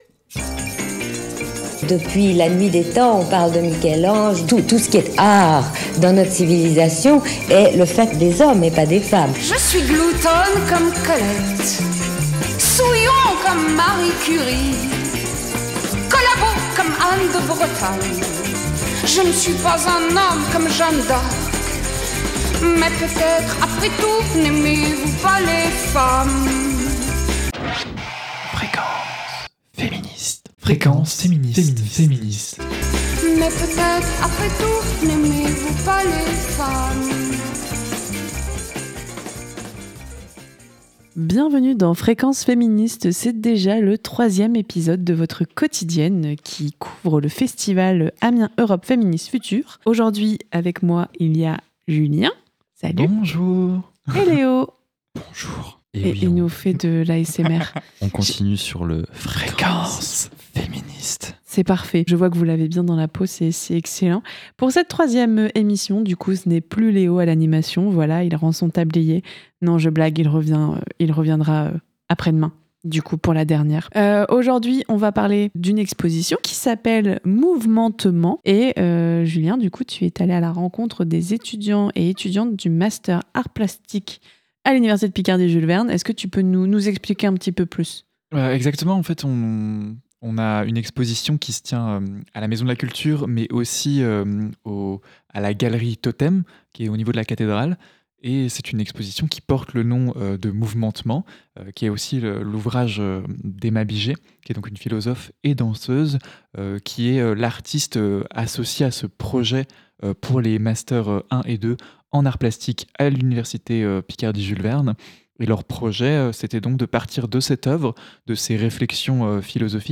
Au programme donc : interviews, chroniques, suggestions littéraires … vous attendent pour profiter avec nous de ces moments de partage et de rencontre.